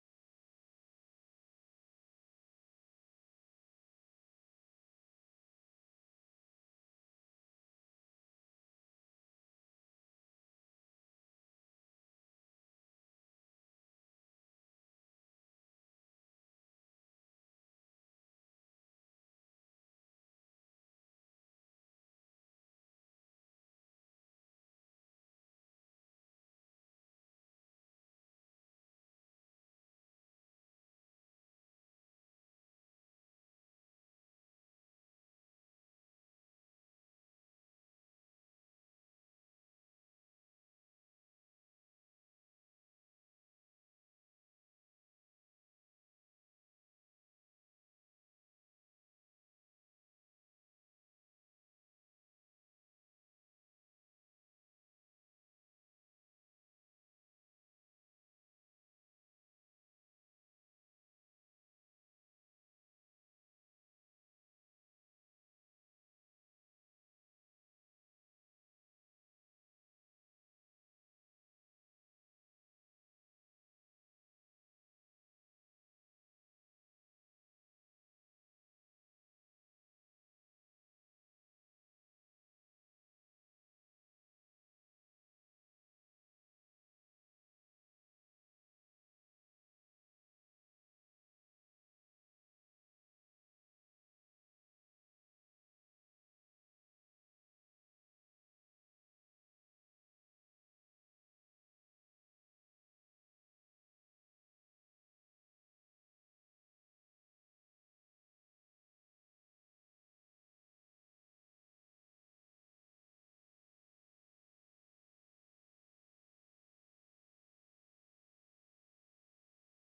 Um debate sobre temas actuais da África Lusófona.